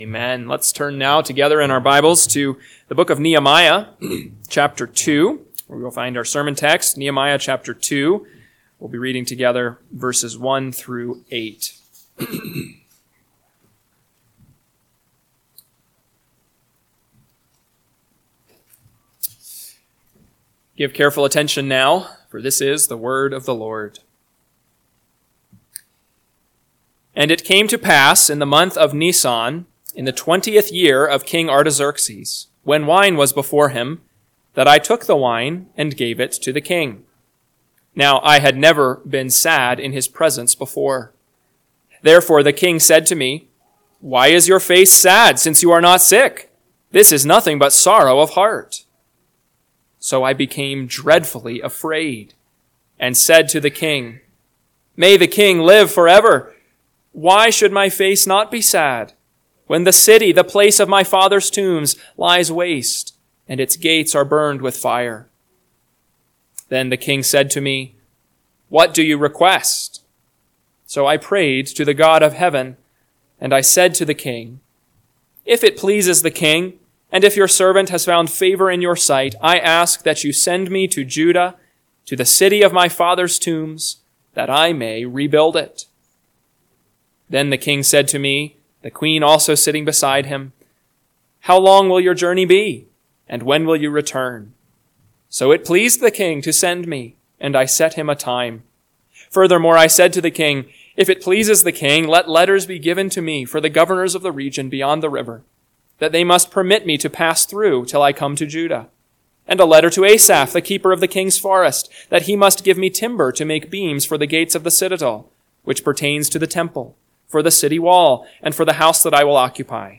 PM Sermon – 6/22/2025 – Nehemiah 2:1-8 – Northwoods Sermons